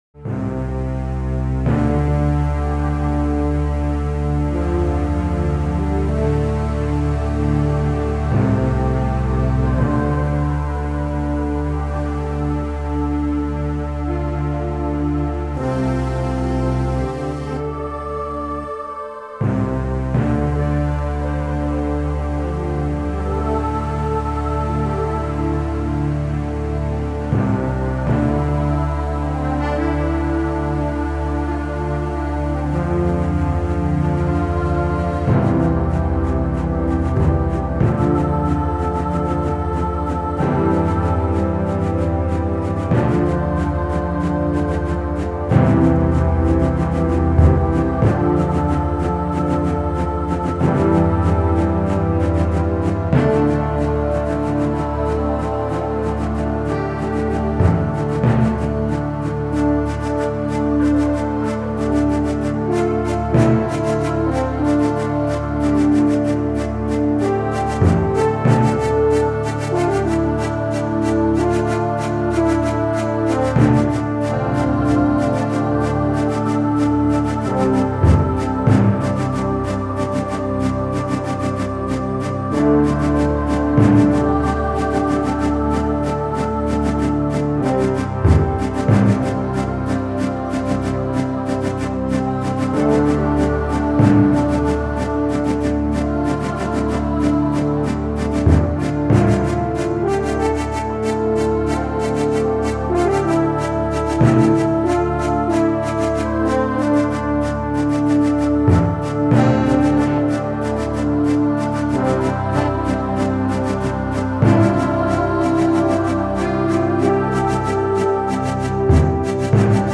Epic Soundtrack.